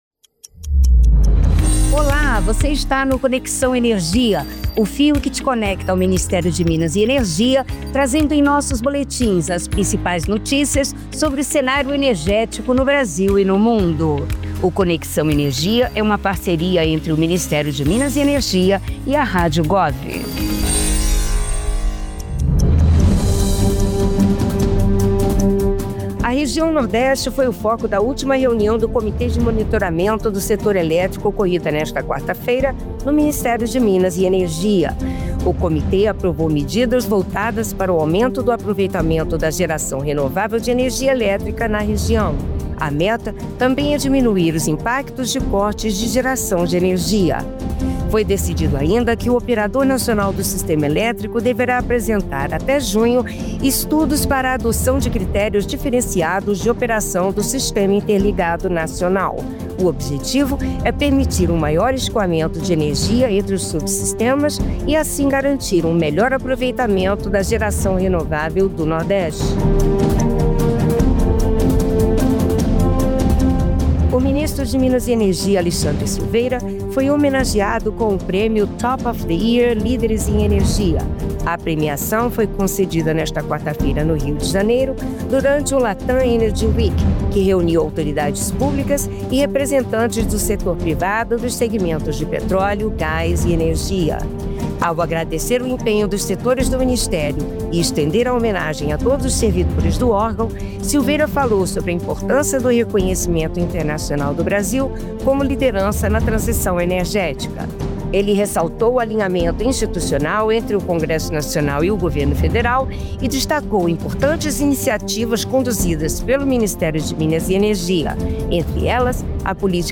Boletim produzido pela assessoria de comunicação do Ministério de Minas e Energia com as atualizações do setor.